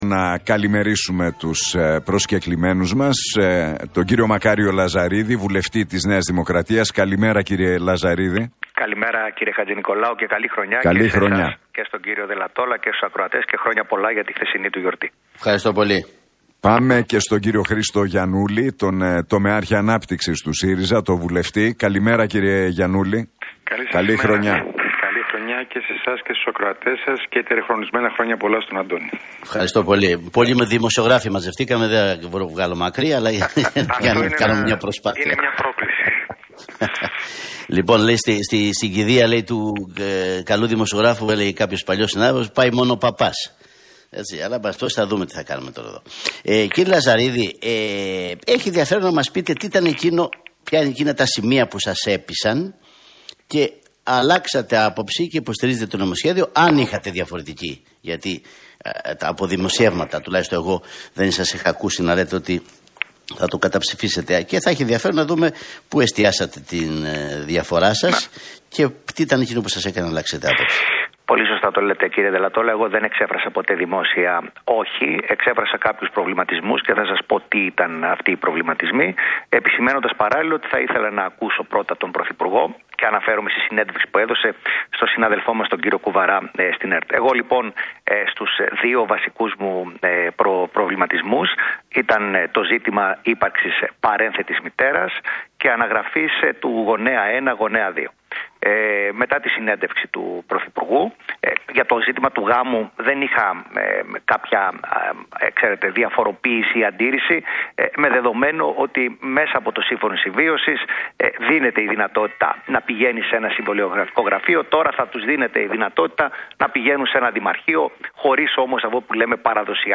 Debate Λαζαρίδη - Γιαννούλη στον Realfm 97,8